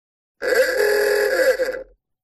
Goofy Ahh Old Car